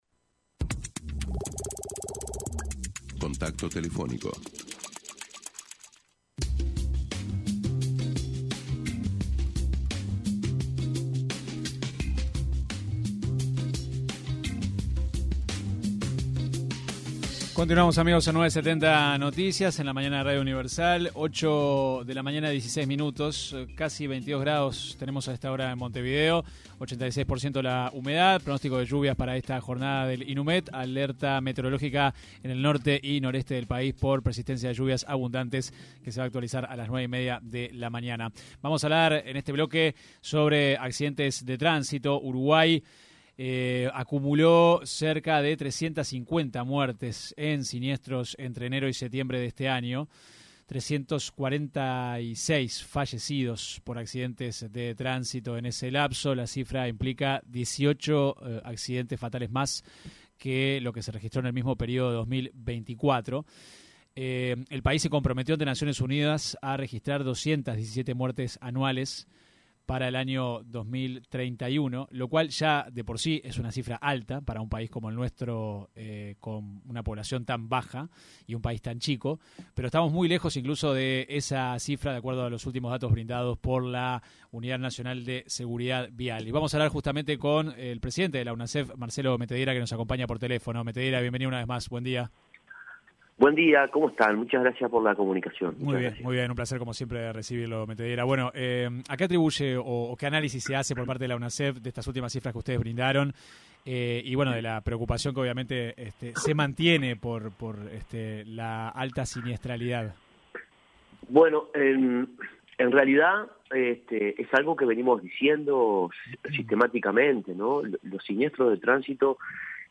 Metediera hizo referencia en entrevista con 970 Noticias al informe del organismo que destaca que la colocación de radares generó un descenso en la cantidad de personas lesionadas y fallecidas en siniestros de tránsito.